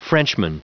Prononciation du mot frenchman en anglais (fichier audio)
Prononciation du mot : frenchman